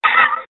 描述：声音说你好，来自坟墓。